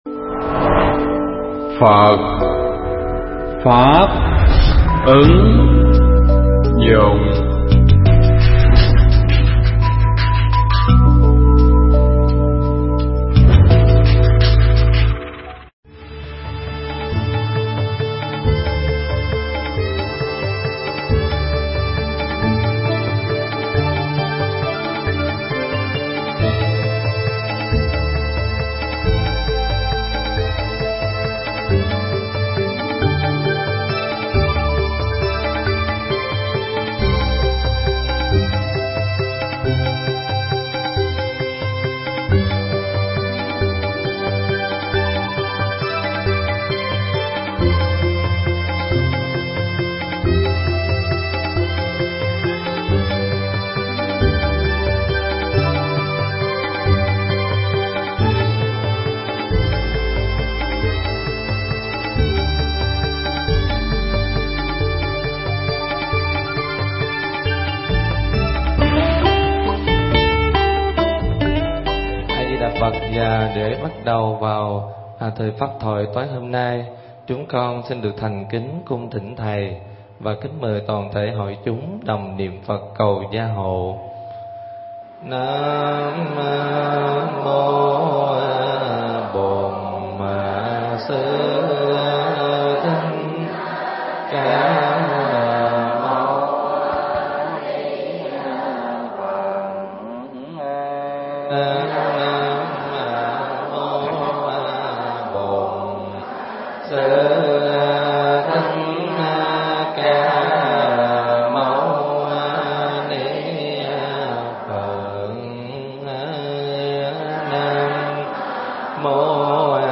Bài pháp âm Kinh Pháp Cú Phẩm Phật Đà (Câu 193 đến câu 196)
thuyết giảng tại tu viện Tường Vân